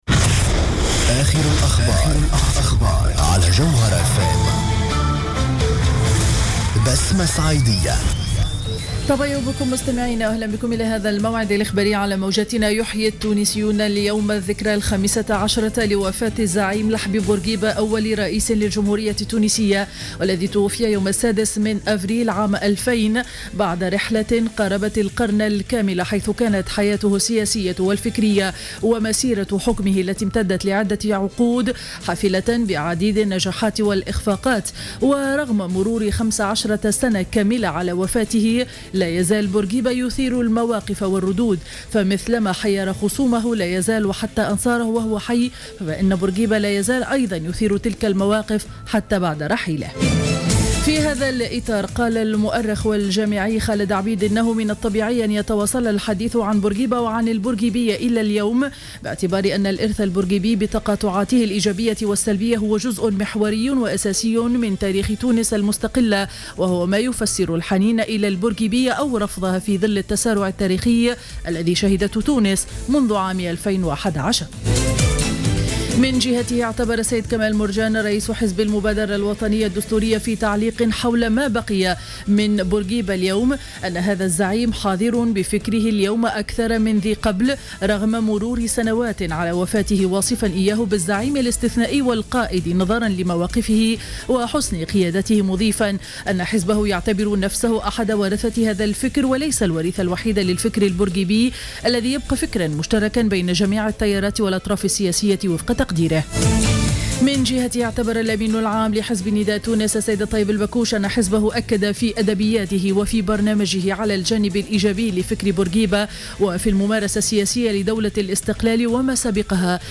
نشرة أخبار السابعة صباحا ليوم الاثنين 6 أفريل 2015